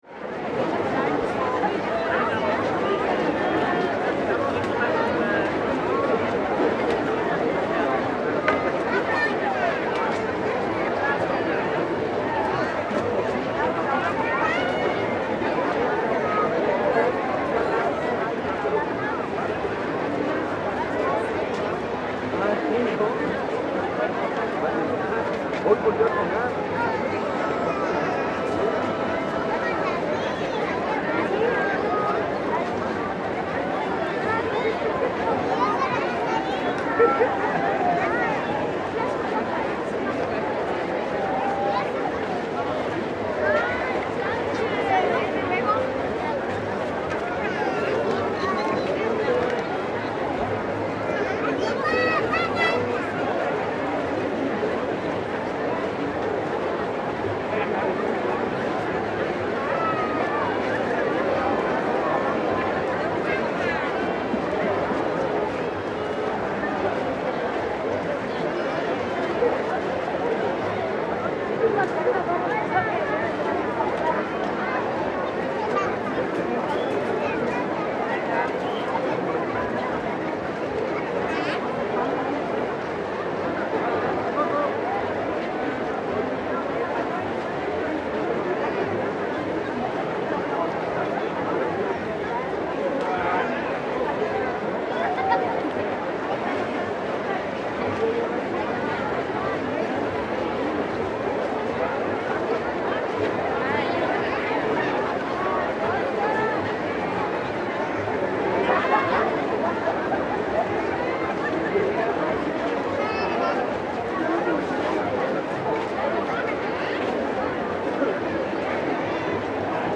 Day of the dead at Oaxaca cemetery
Day of the death at Panteón General cemetery at Oaxaca. Stereo 48kHz 24bit.